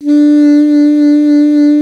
55af-sax05-D3.wav